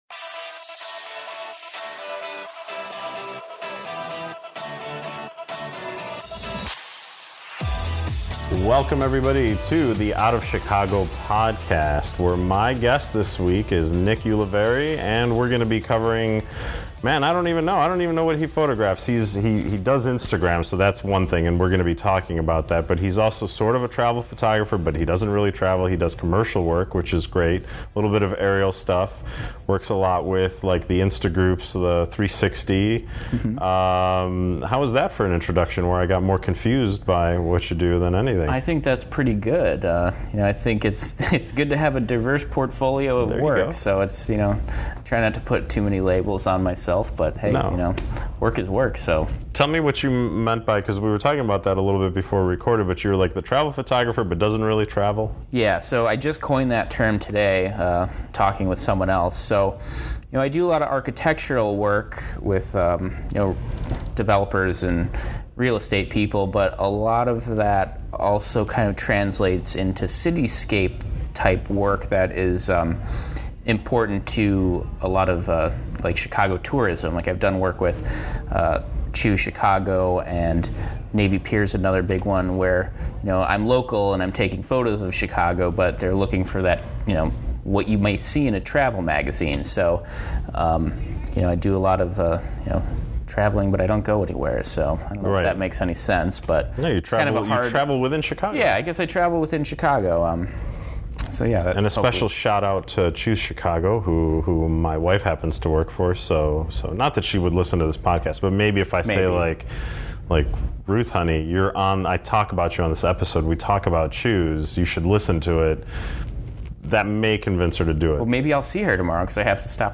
during the Out of Chicago Conference